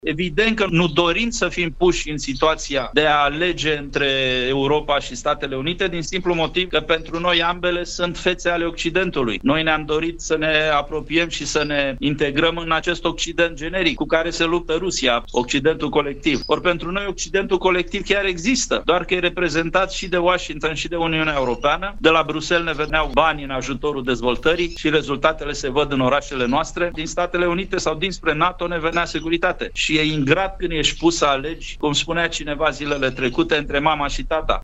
Poziția României privind susținerea, la ONU, atât a rezoluției UE, cât și a rezoluției Statelor Unite, privind încetarea războiului din Ucraina, este de înțeles, a declarat, la Radio Timișoara, fostul ministru de Externe, istoricul Adrian Cioroianu.